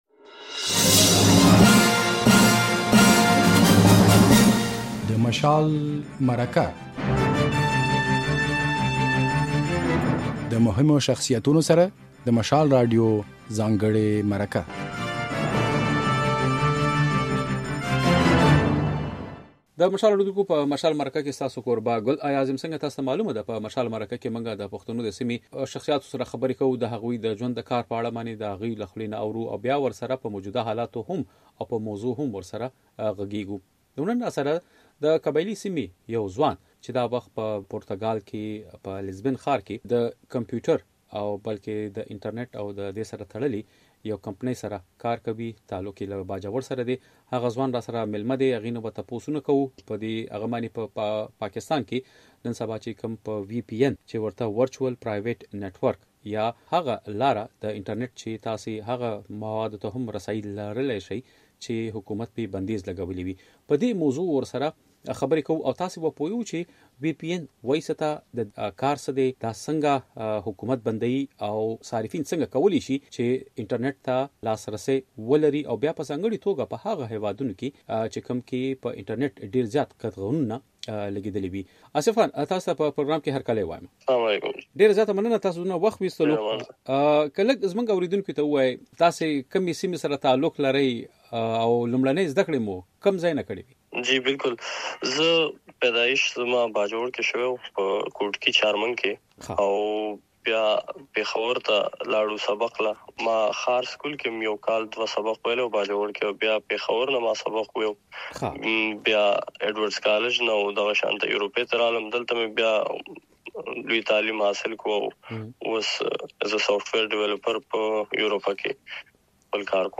د نوموړي په خبره، اوسنی دور د ټېکنالوژۍ دی چې پکې پر انټرنېټ بندیز لګول یا محدودول د هېواد په ګټه نه تمامېږي. بشپړه مرکه واورئ.